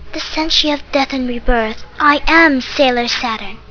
Sense we haven't started the dub yet I put up audition clips.